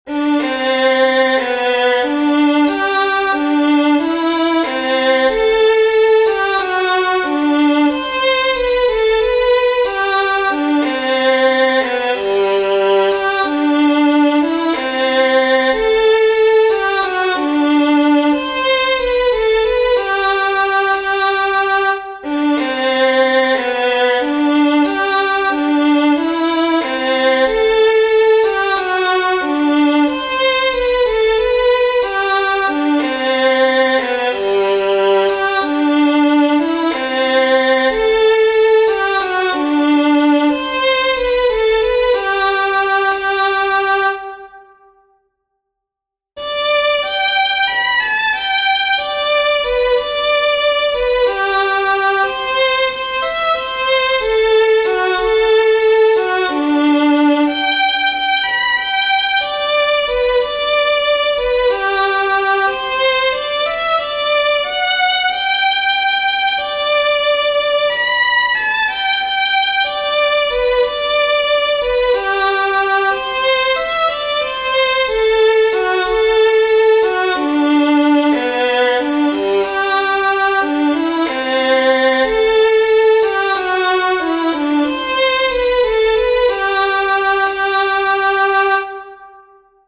His tunes cover the range of speed from very slowly played slow airs such as Going Back Home, which depicts a farm worker slowly walking home down a red dirt road after a hard day’s work, and reels that he played breathtakingly fast and accurately.